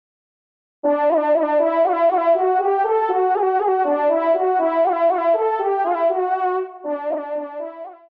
FANFARE